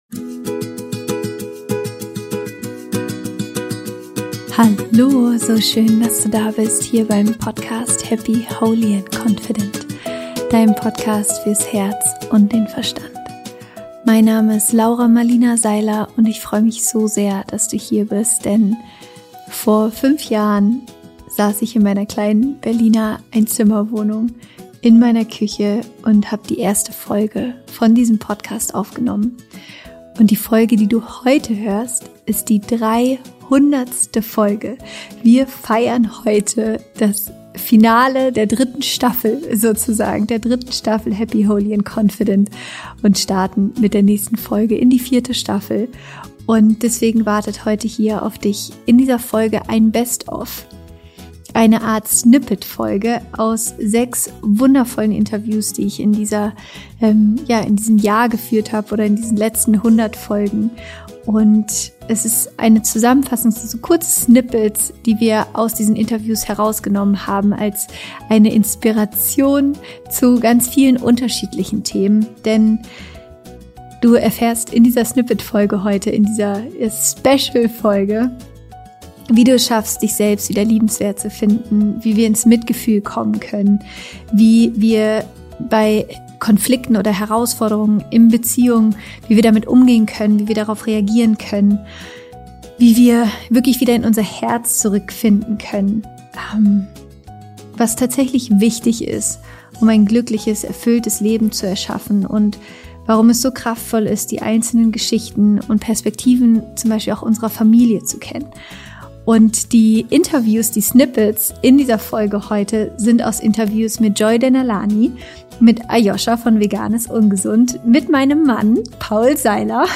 Ich habe mir überlegt, dass ich gerne ein paar der schönsten Interview-Ausschnitte mit dir teilen möchte.